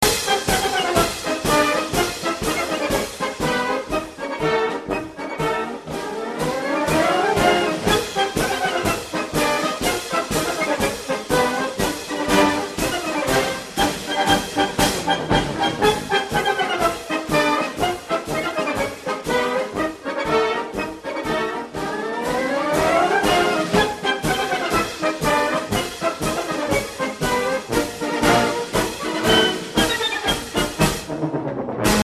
Цирковая_музыка_-_клоунская.mp3